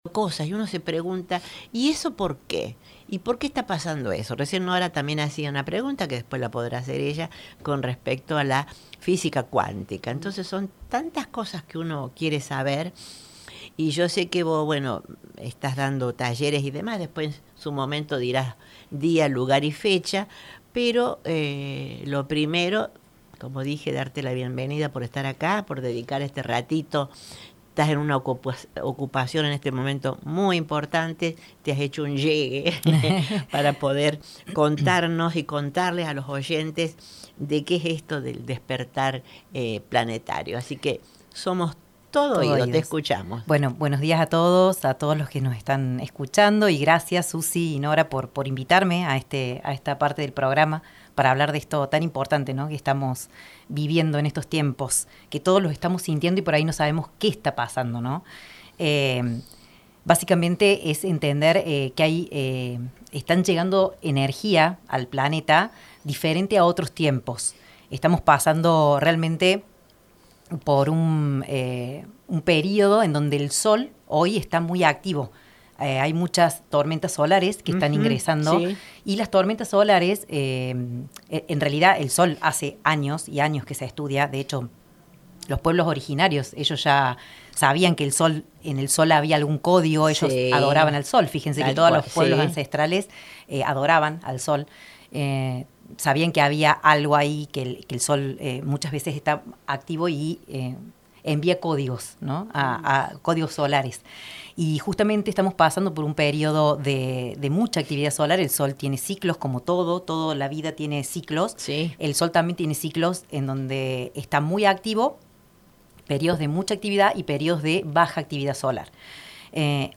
Entrevista ''Celebra la Vida'': ¿Qué es el Despertar Planetario?